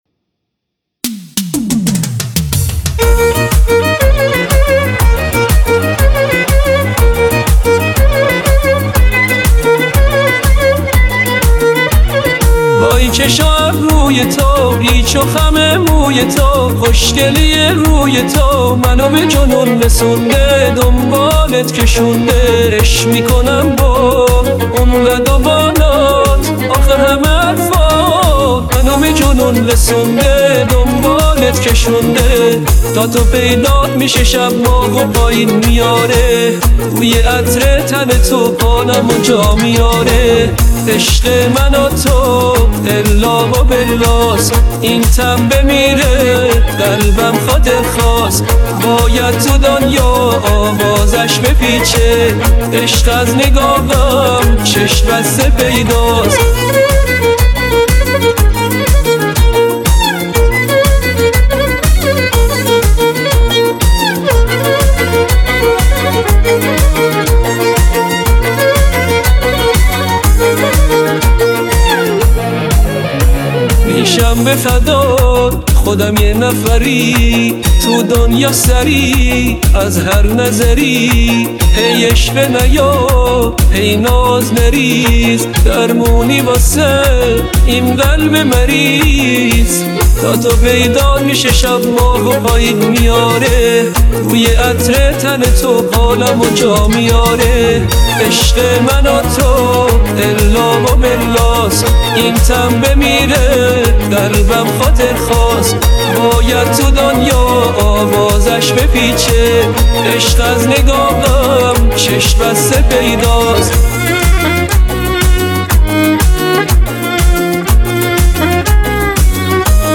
آهنگ پاپ ایرانی دانلود آهنگ های هوش مصنوعی